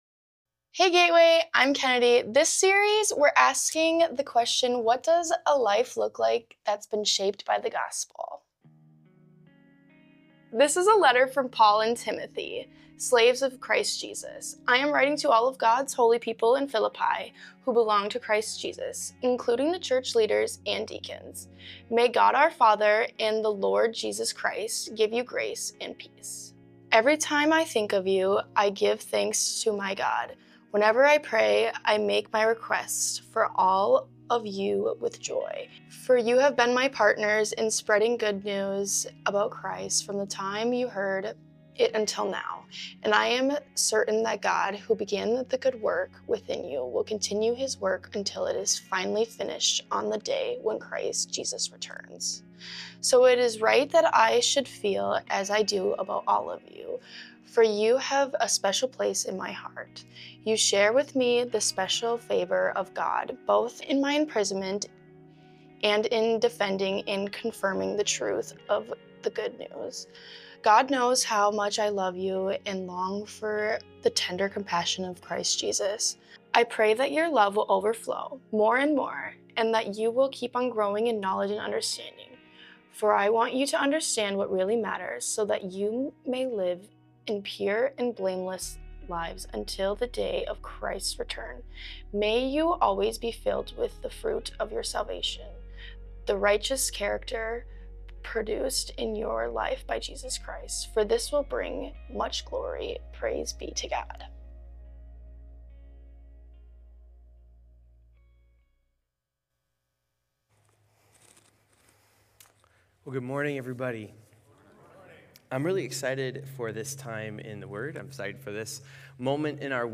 The-Water-is-Real-Sermon-4.27.25.m4a